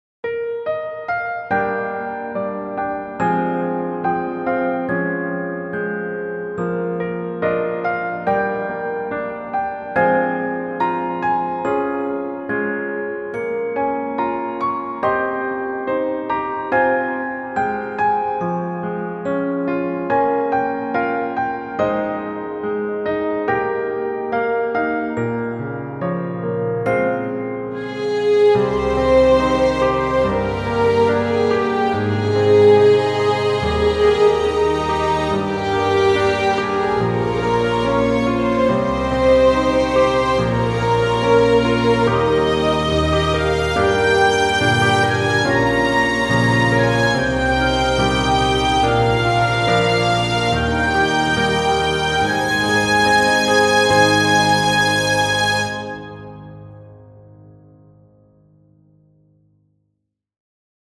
ピアノ ストリングス バラード ヒーリング 別れ 切ない 静か 癒し 落ち着く 感動 穏やか